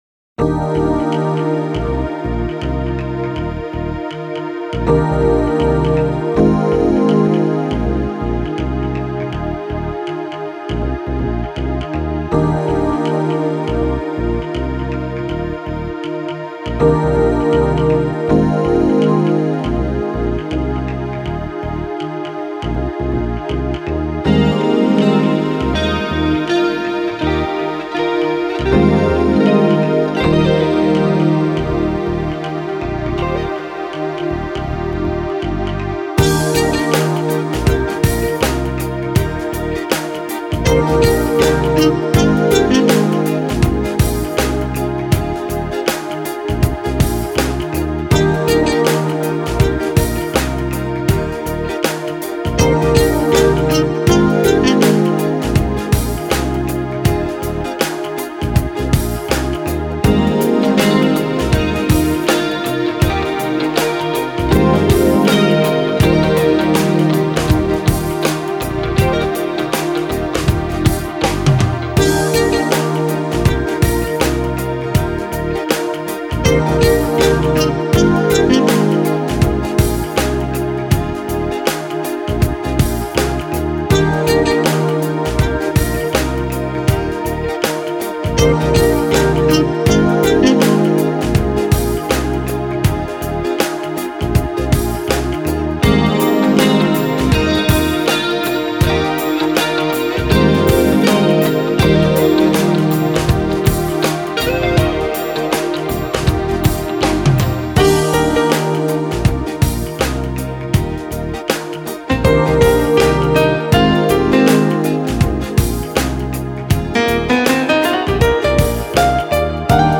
calming